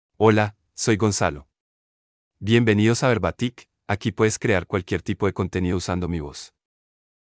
MaleSpanish (Colombia)
Gonzalo — Male Spanish AI voice
Gonzalo is a male AI voice for Spanish (Colombia).
Voice sample
Gonzalo delivers clear pronunciation with authentic Colombia Spanish intonation, making your content sound professionally produced.